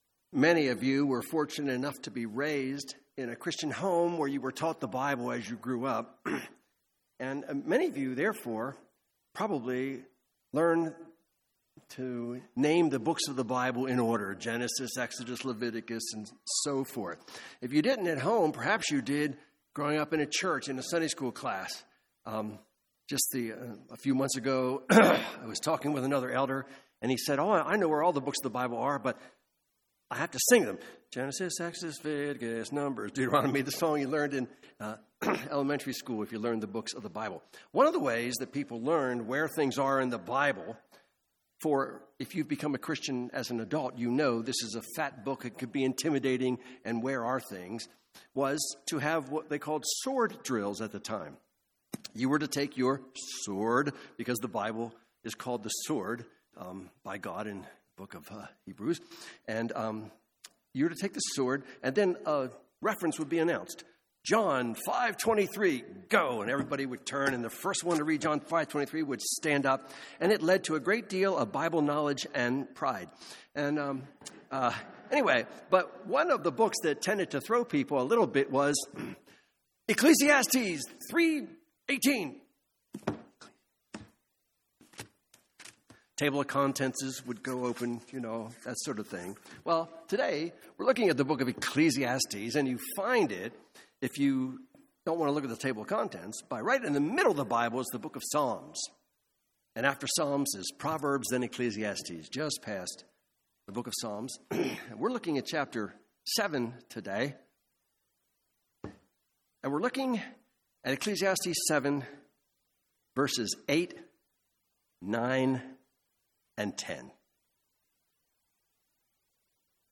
Ecclesiastes — Audio Sermons — Brick Lane Community Church